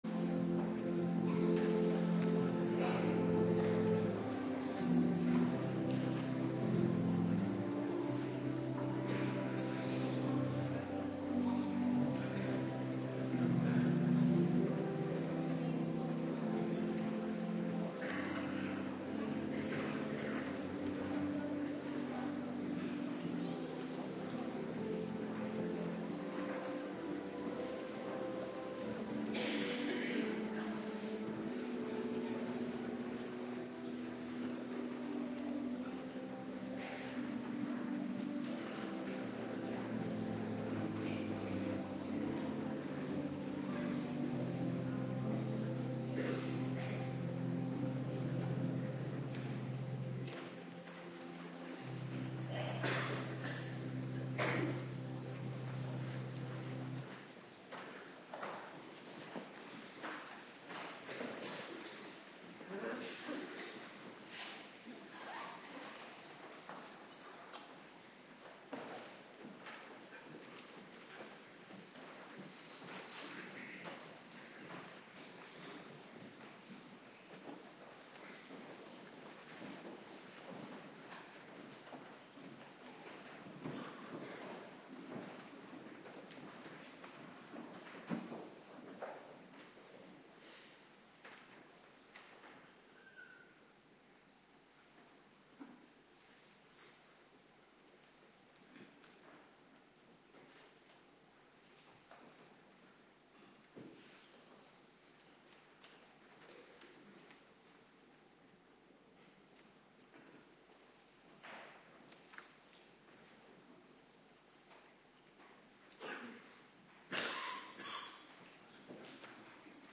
Morgendienst
Locatie: Hervormde Gemeente Waarder